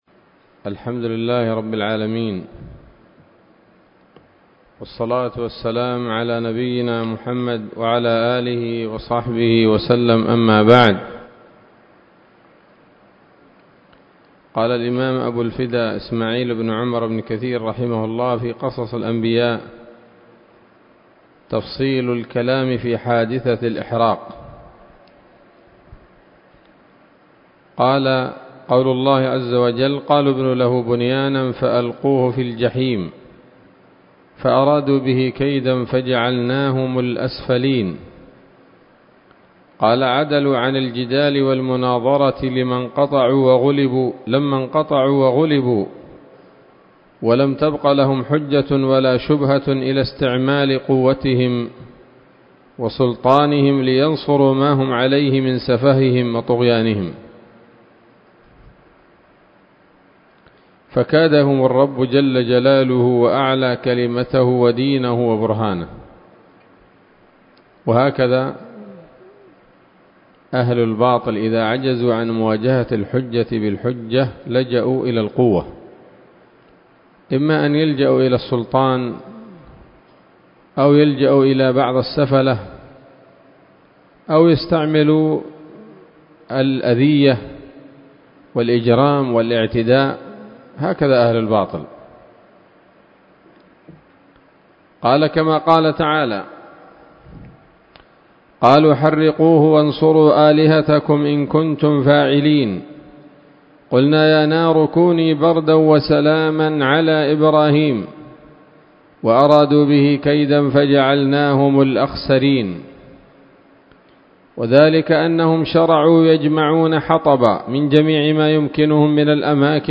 الدرس الثالث والأربعون من قصص الأنبياء لابن كثير رحمه الله تعالى